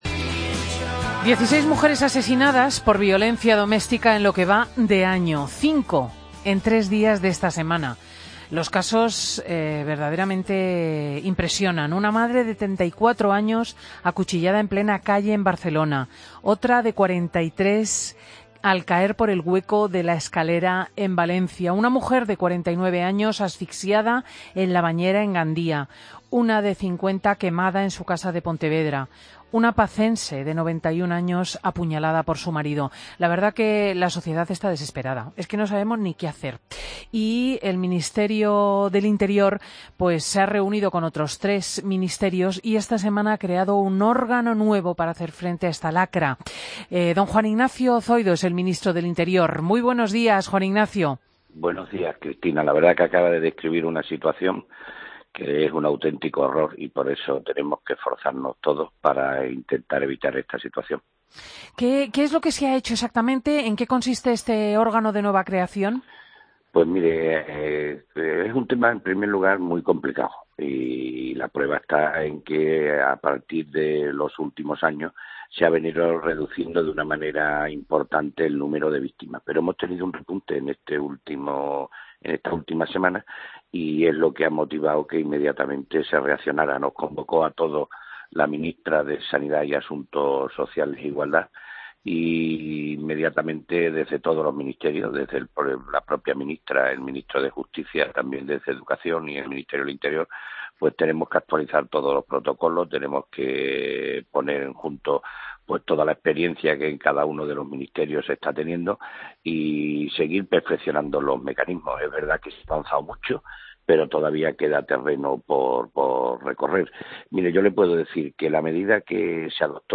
AUDIO: Escucha la entrevista a Juan Ignacio Zoido, ministro del Interior, en Fin de Semana